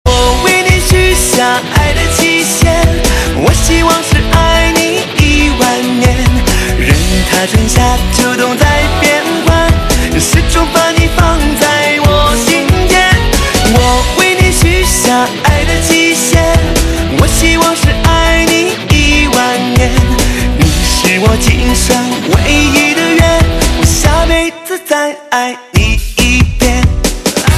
华语歌曲